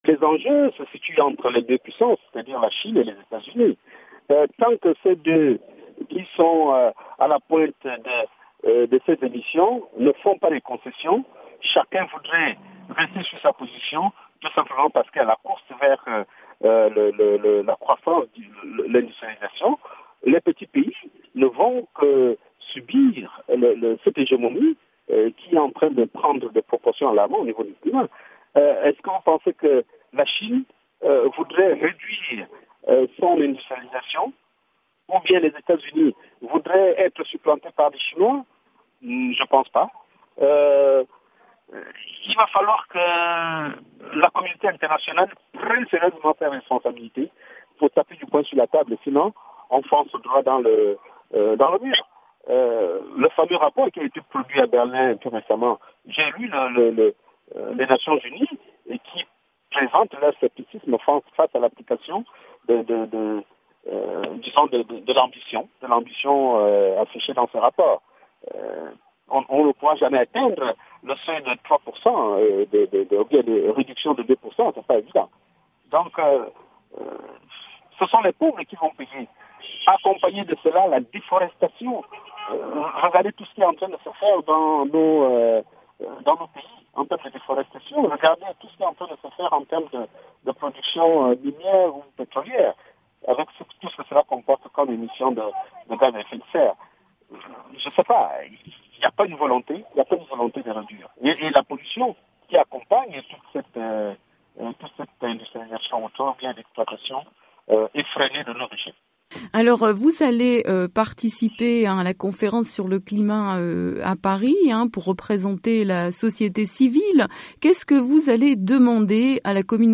Joint par VOA Afrique, il dénonce également la destruction des forêts tropicales et notamment le braconnage des éléphants dans son pays.